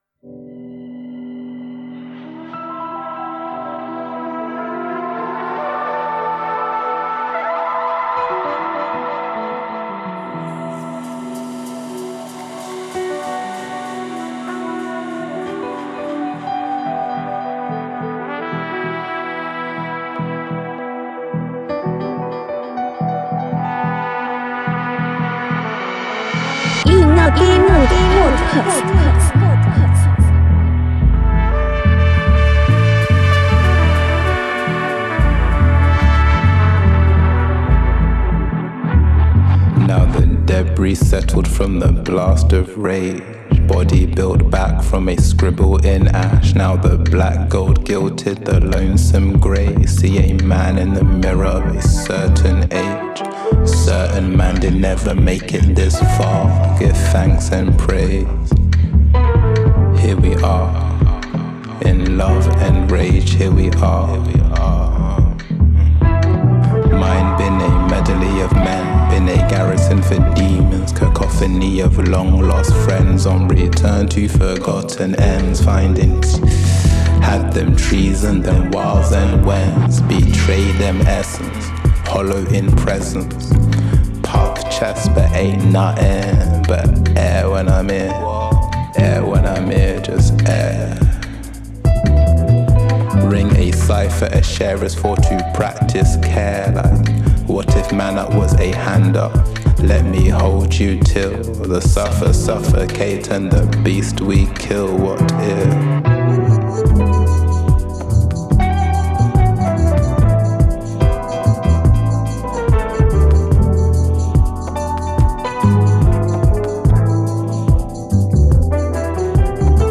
Reggae, Hip-Hop, Afro Beats & Quality Music